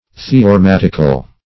Search Result for " theorematical" : The Collaborative International Dictionary of English v.0.48: Theorematic \The`o*re*mat"ic\, Theorematical \The`o*re*mat"ic*al\, a. [Cf. Gr.